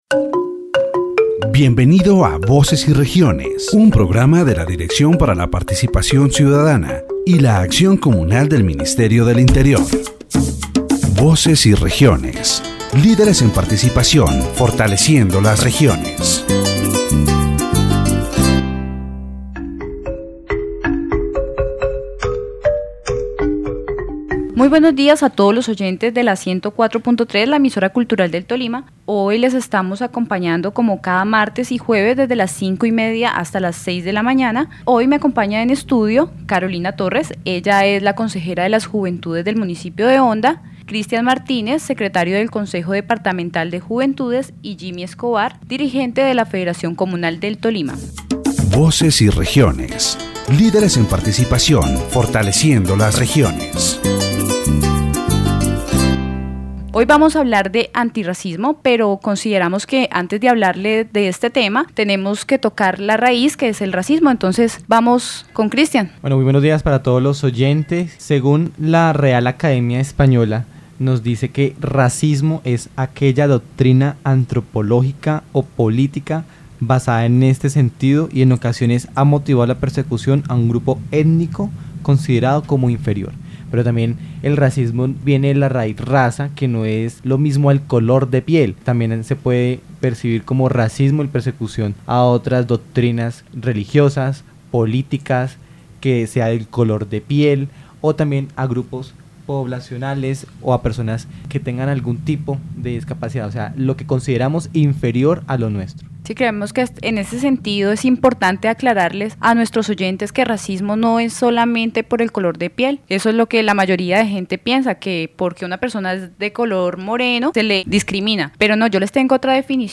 In this section of the Voces y Regiones program, the hosts discuss and define the word "racism." They first reference the definition from the Royal Spanish Academy (RAE) and also explore its global concept.